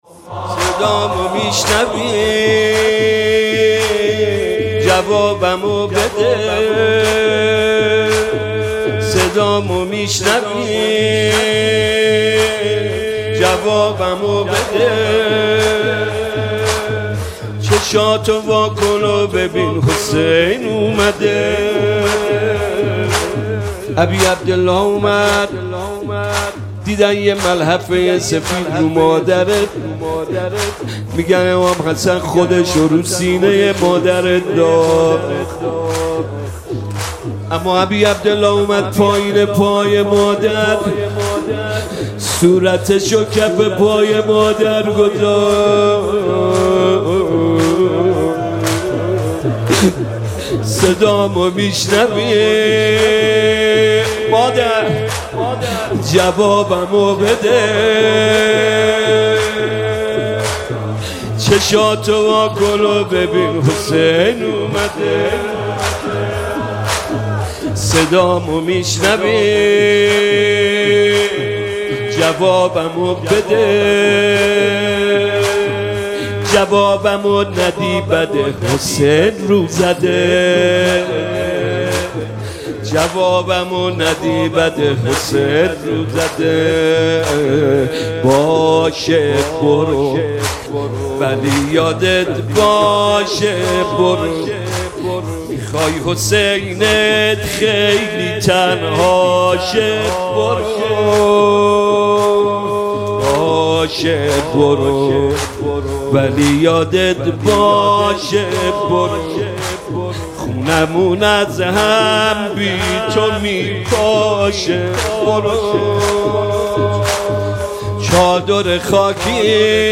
مداحی دلنشین